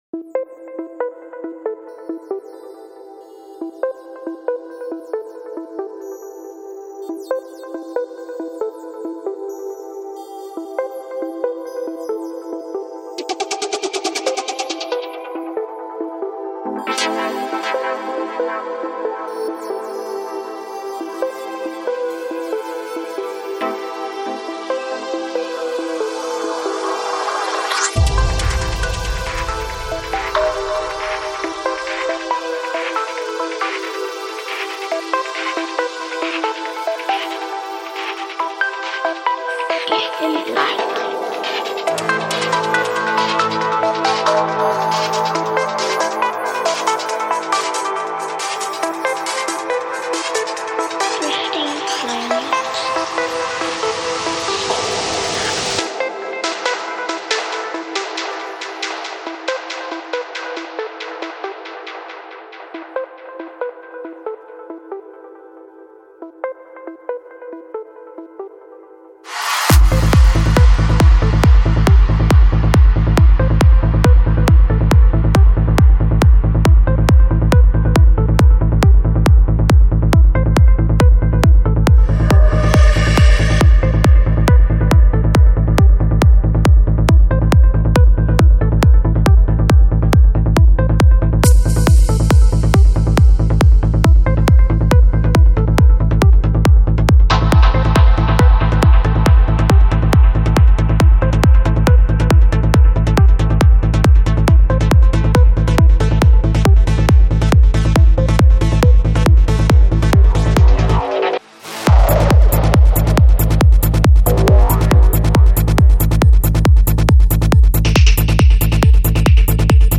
Альбом: Psy-Trance